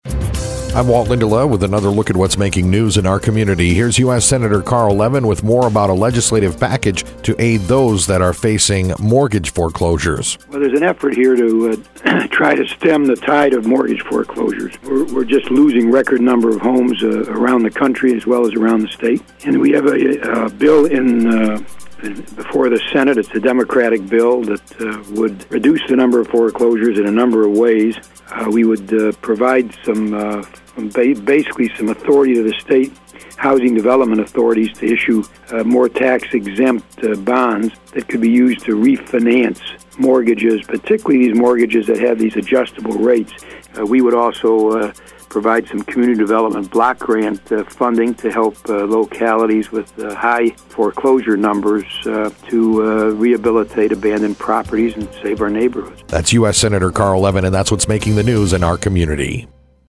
Discussion with the Senator about a Democratic measure currently being filibustered in the US Senate by Republicans that calls for certain loan measures and bond proposals that would protect homeowners against ballooning mortgage foreclosures.